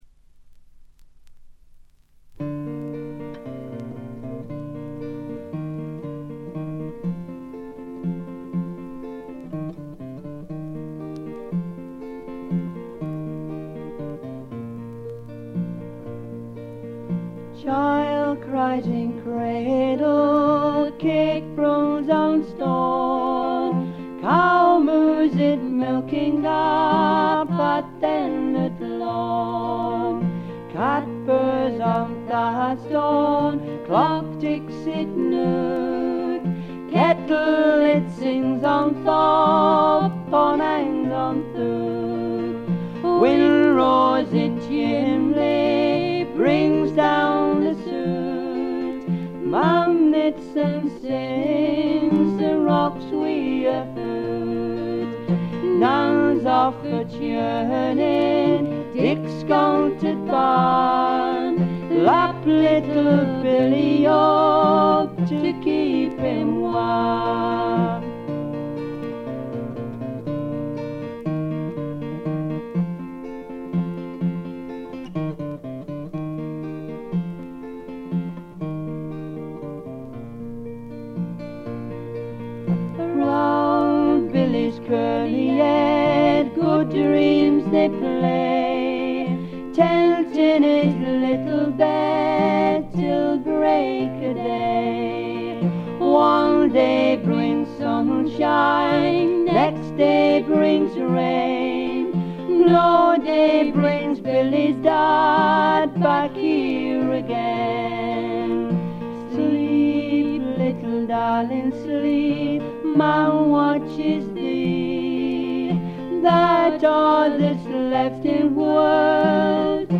部分試聴ですが、ほとんどノイズ感無し。
若々しさ溢れるヴォーカルがとてもよいですね。
ごくシンプルな伴奏ながら、躍動感が感じられる快作！
コンサーティナの哀愁と郷愁をかきたてる音色がたまりません。
試聴曲は現品からの取り込み音源です。